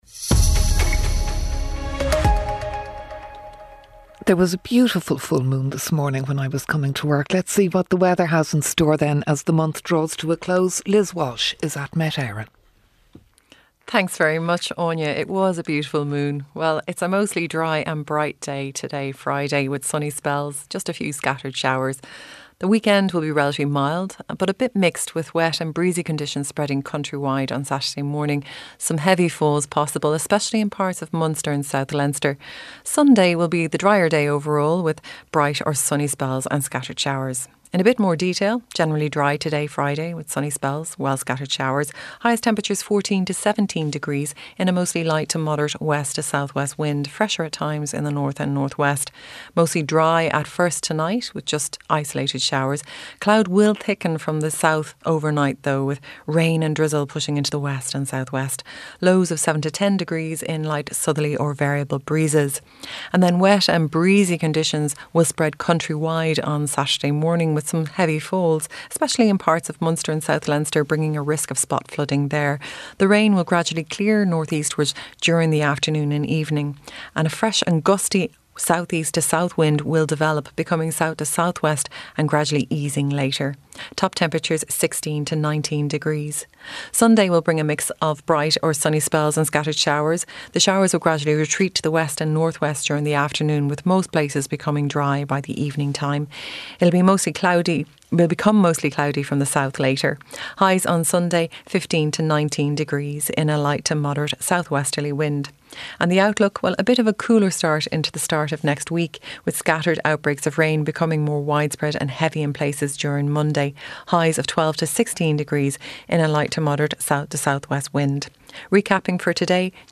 7:35am Sports News - 29.09.2023